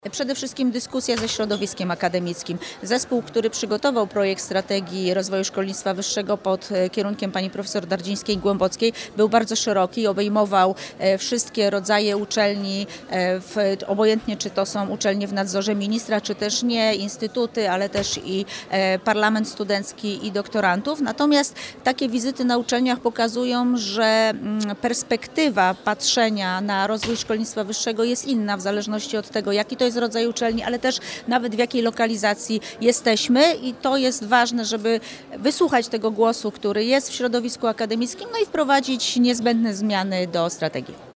O jego głównych założeniach mówi prof. Maria Mrówczyńska, wiceminister nauki i szkolnictwa wyższego.